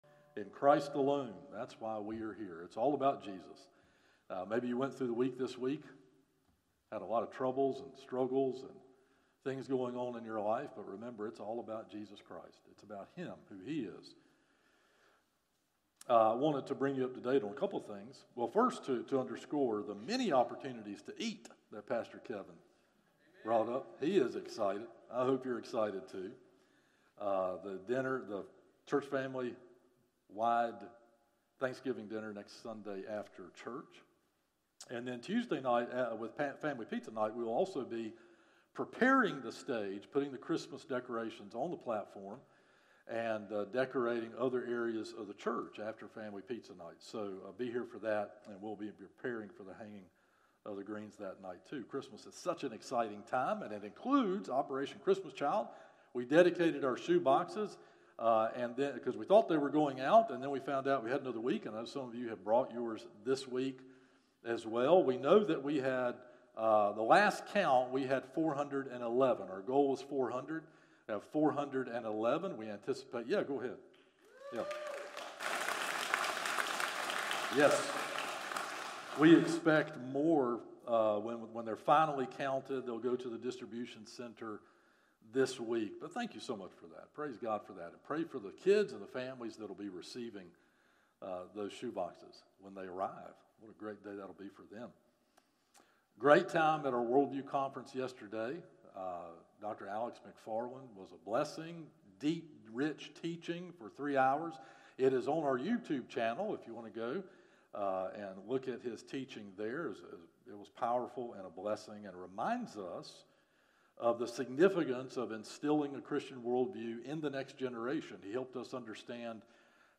Sermons - First Baptist Church of Shallotte
From Series: "Morning Worship - 11am"